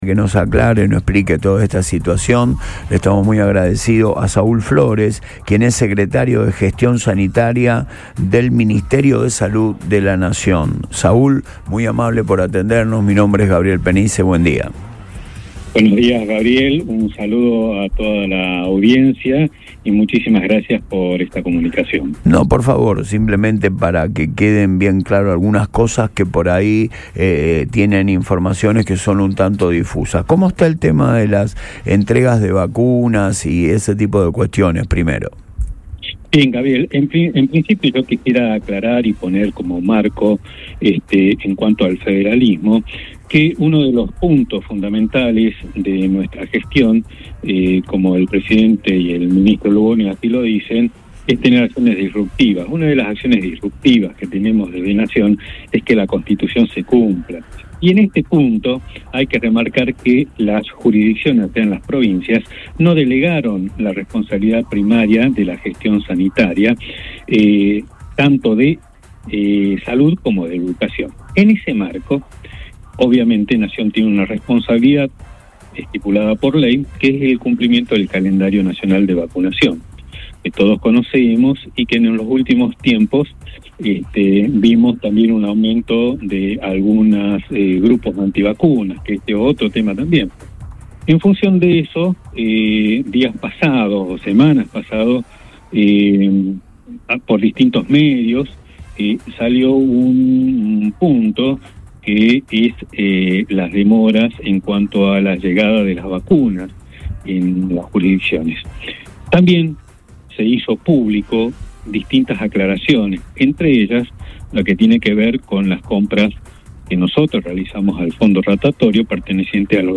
En diálogo con el programa radial Antes de Todo, de Boing 97.3, el funcionario explicó que las dificultades estuvieron relacionadas con la distribución global y los convenios de compra realizados a través del Fondo Rotatorio de la OPS, dependiente de la Organización Panamericana de la Salud.